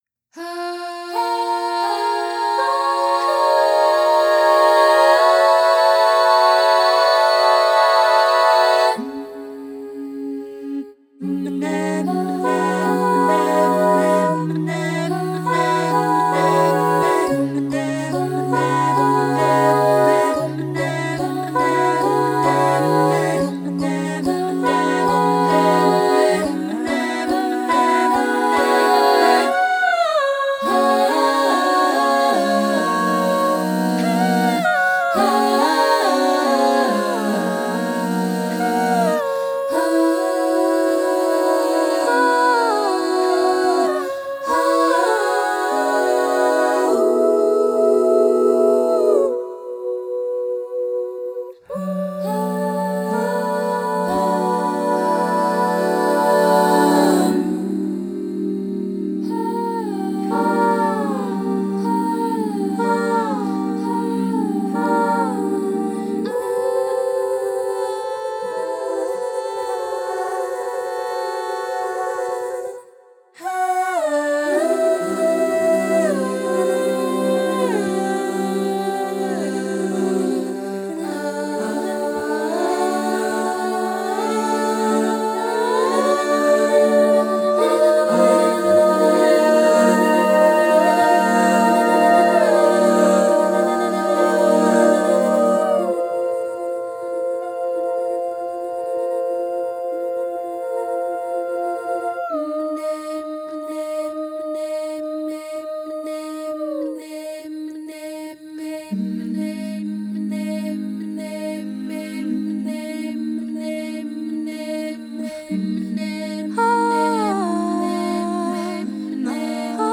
Big, bold, ambitious, colorful,
for SA div. choir a cappella
A great work for an advanced treble chorus to dig into.